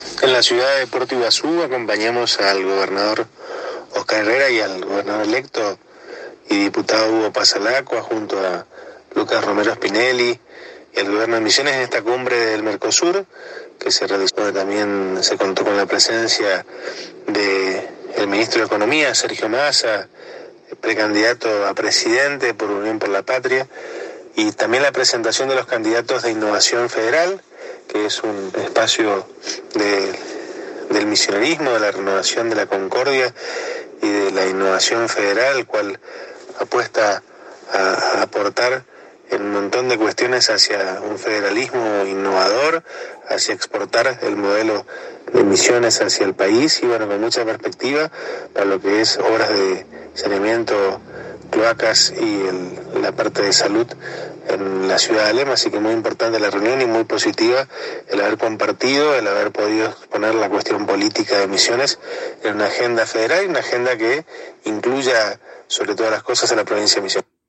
El intendente electo de Alem, Dr. Matías Sebely, en diálogo exclusivo con la ANG, relató el acompañamiento que realizó al gobernador Oscar Herrera y al electo Hugo Passalacqua en la reunión que se realizó con todos los candidatos a legisladores nacionales de Innovación Federal.
Audio: Matias Sebely, Intendente electo de Alem